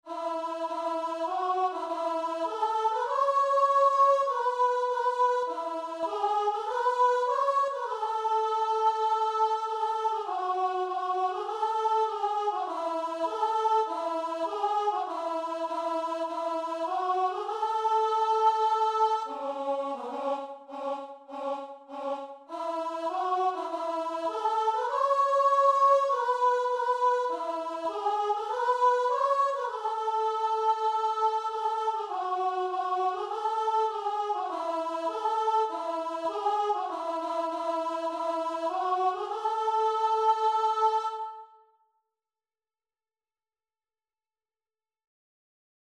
Christian Christian Guitar and Vocal Sheet Music
4/4 (View more 4/4 Music)
Classical (View more Classical Guitar and Vocal Music)